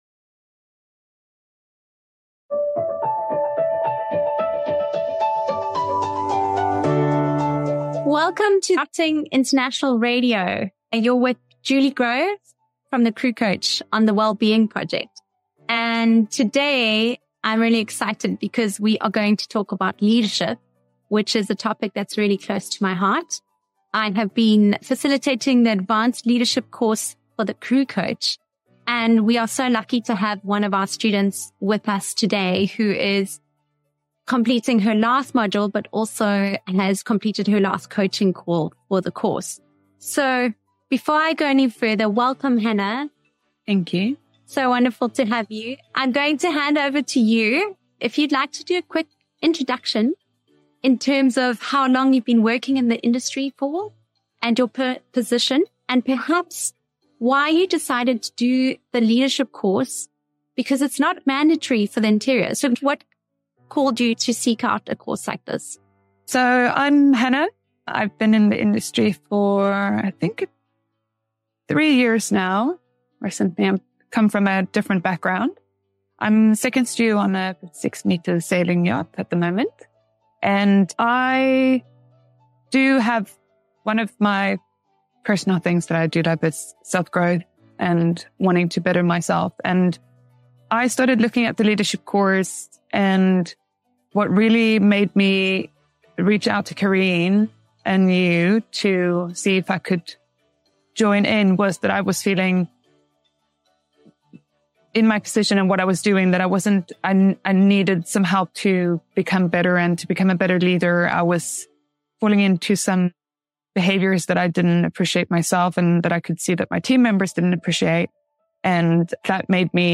This insightful conversation delves into effective communication, feedback dynamics, aligning with personal values, goal setting, and the transformative power of constructive feedback.